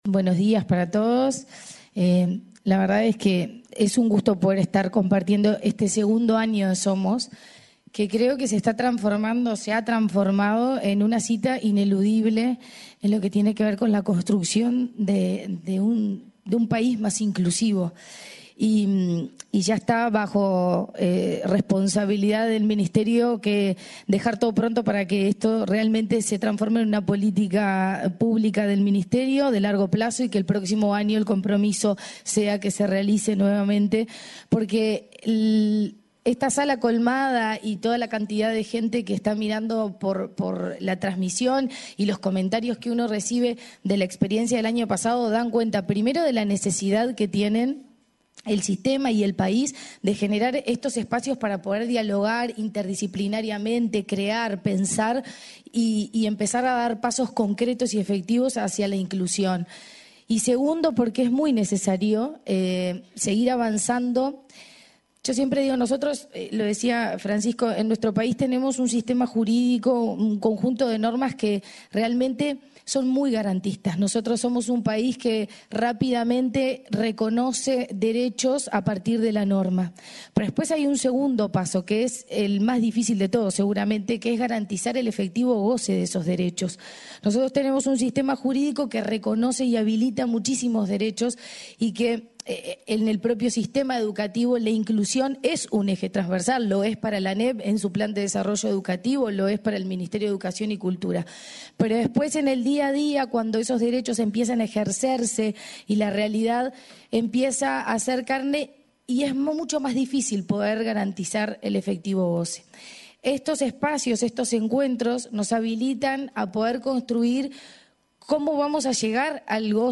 Palabras de autoridades en Congreso de Educación Inclusiva
Palabras de autoridades en Congreso de Educación Inclusiva 19/08/2024 Compartir Facebook X Copiar enlace WhatsApp LinkedIn La presidenta de la Administración de Educación Pública (ANEP), Virginia Cáceres, y el ministro de Educación y Cultura, Pablo da Silveira, participaron en la apertura del Congreso Internacional de Educación Inclusiva, que se realiza este lunes 19 y el martes 20 en el hotel Radisson Victoria Plaza de Montevideo.